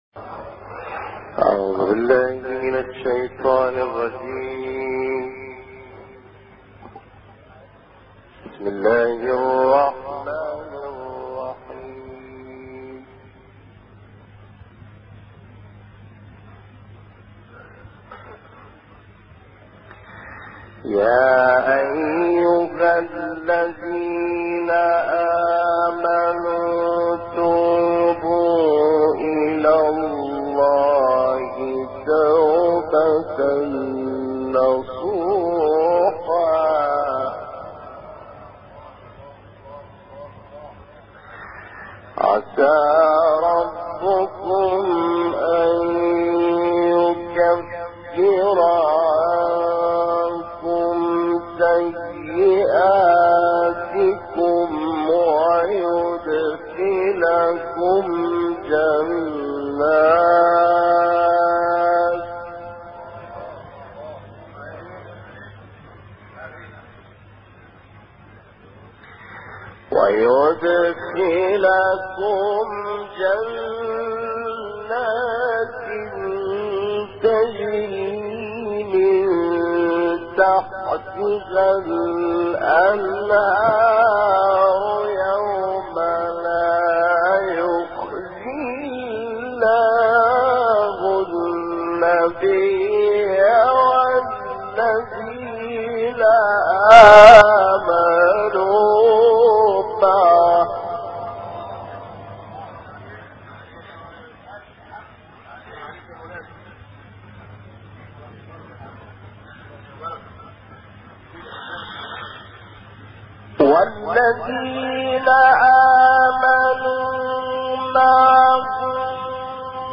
تلاوت «اللیثی» در سال 1996 میلادی